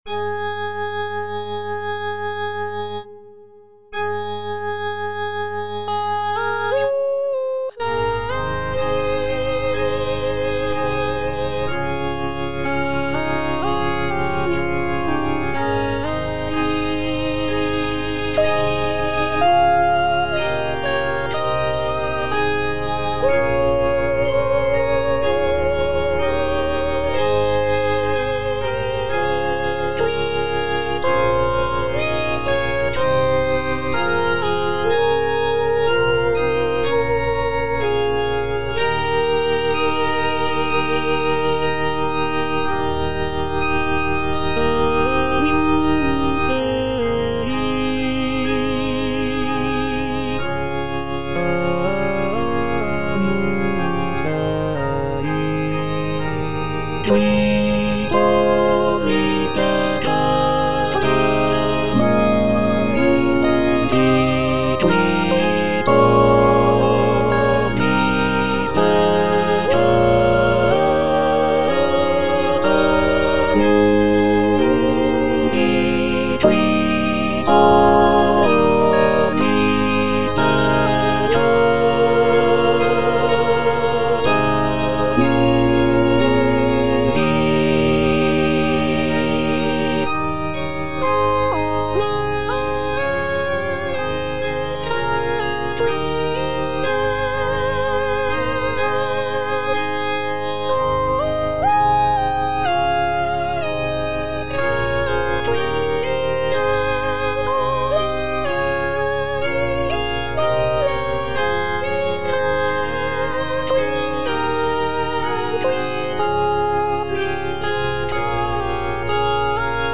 All voices
is a sacred choral work rooted in his Christian faith.